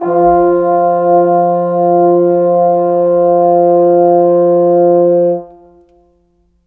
Rock-Pop 22 Horns 02.wav